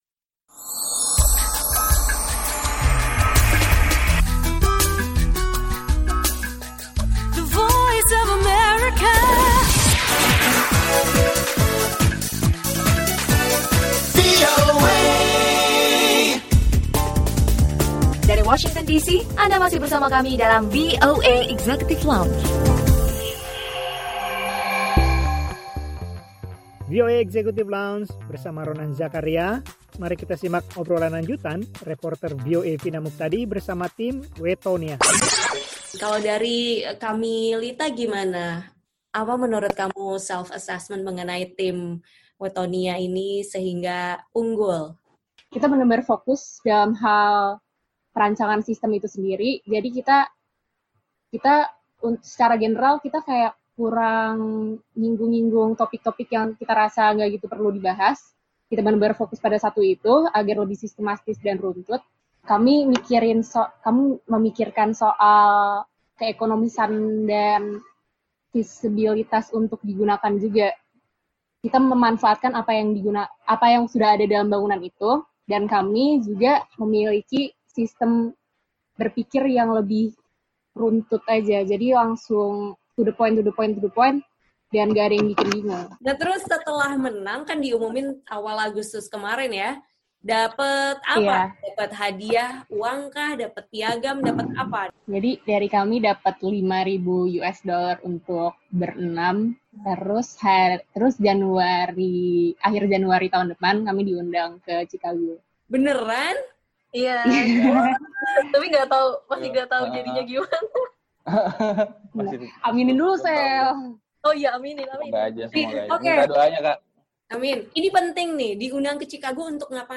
Obrolan lanjutan bersama tim mahasiswa dari Institut Teknologi Bandung pada awal Agustus memenangkan kompetisi desain bangunan yang diadakan oleh ASHRAE, perkumpulan insinyur di Amerika yang bertujuan “memajukan teknologi pemanas, ventilasi, AC dan pendingin untuk lingkungan yang berkelanjutan”.